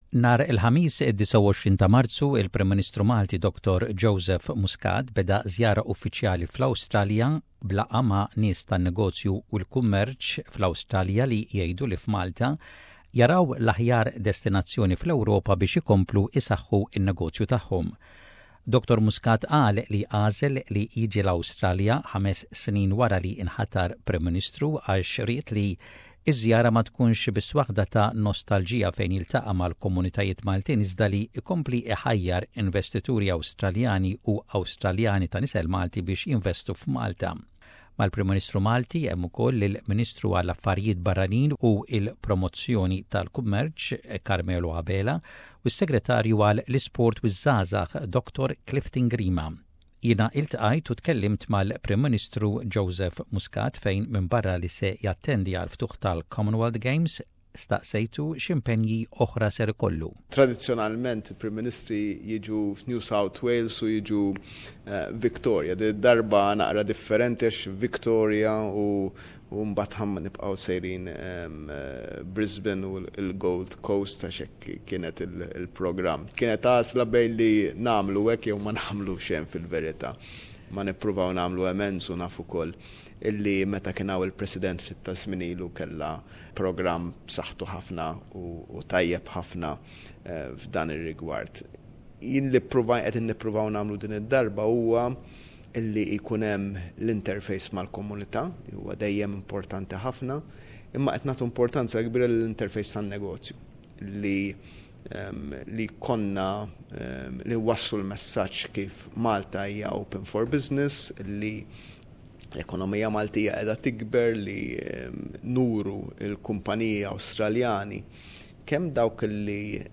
The Prime Minister of Malta, Dr Joseph Muscat speaks